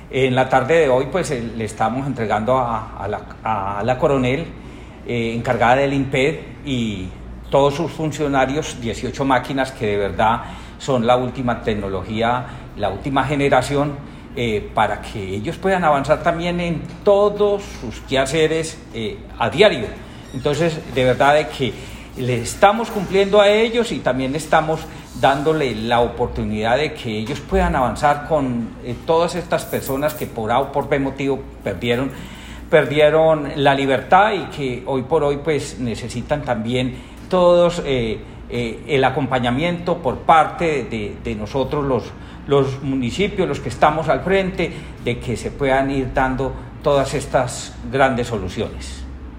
Escuchar Audio: Alcalde Diego Ramos.
Comunicado-035-Audio-Alcalde-Diego-Ramos.m4a